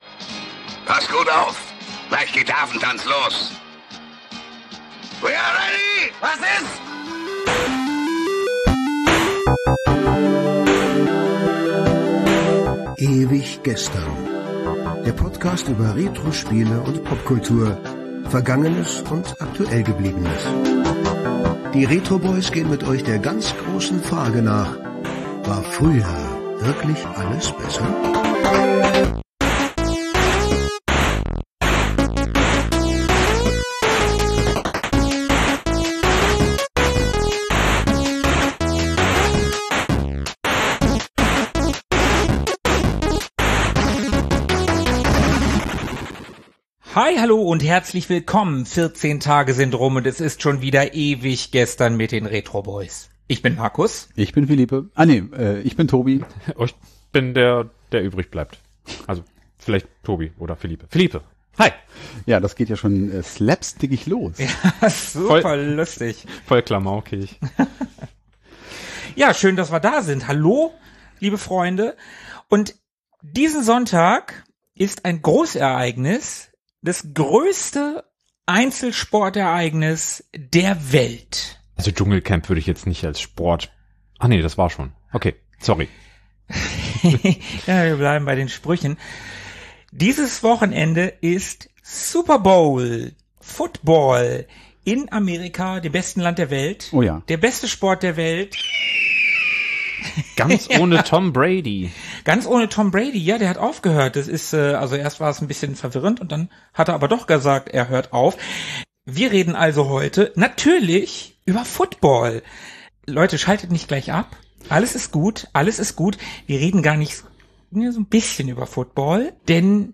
Introsprecher Hans-Georg Panczak (Ja, der.)